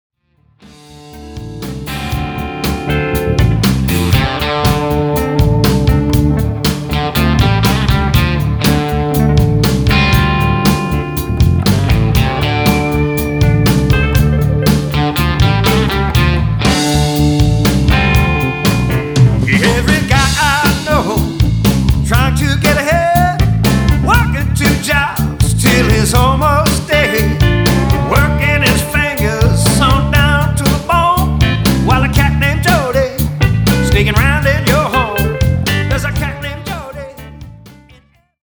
Vocal & Guitar
Digital Piano
Lap Steel Guitar
Recorded at Tony’s Treasures Studio, Cadiz, Ohio.